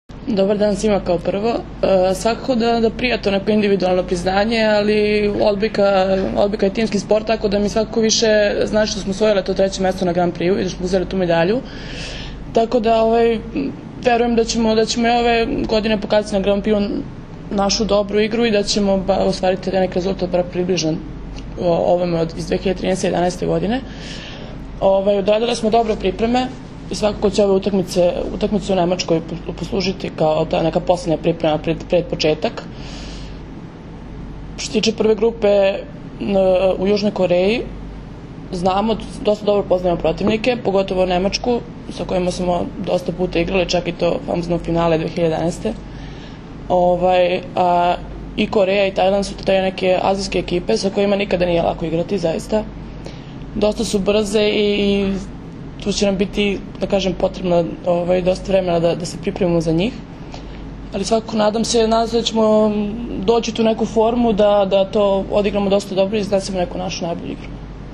Tim povodom, danas je u beogradskom hotelu “M” održana konferencija za novinare, kojoj su prisustvovali Zoran Terzić, Maja Ognjenović, Jelena Nikolić i Milena Rašić.
IZJAVA MILENE RAŠIĆ